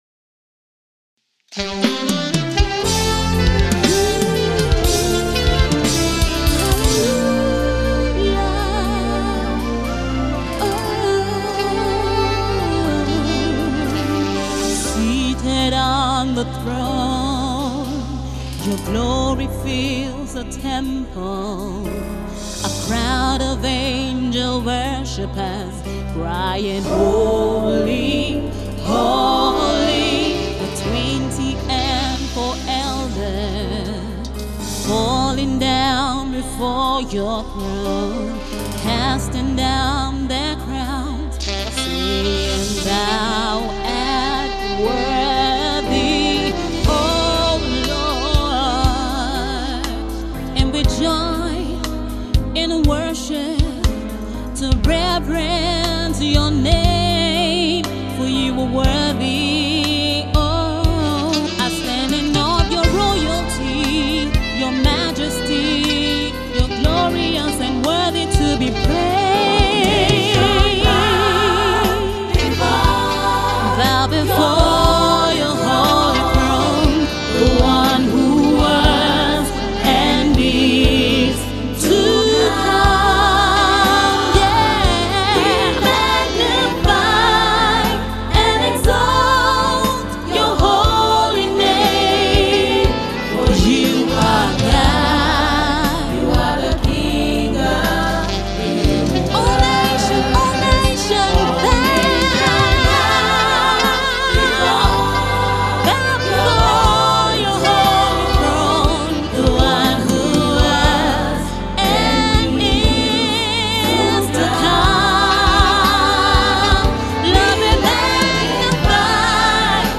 worship single